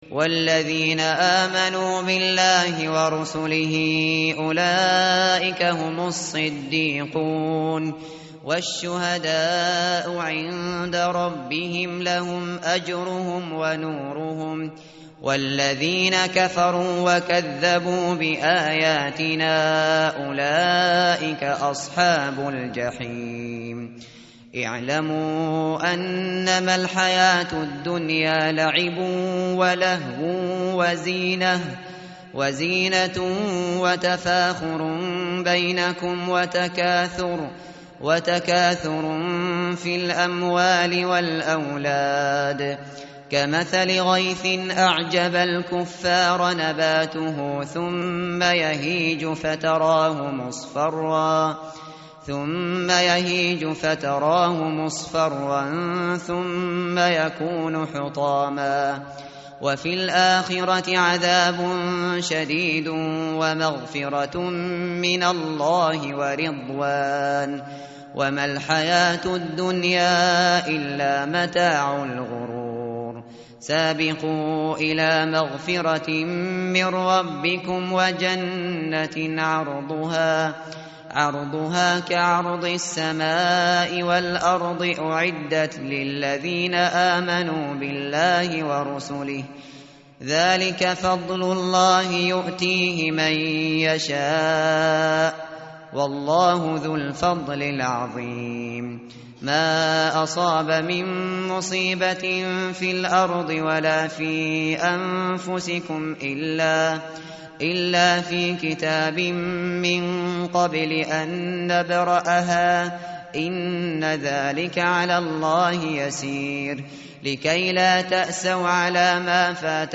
tartil_shateri_page_540.mp3